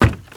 STEPS Wood, Creaky, Run 04.wav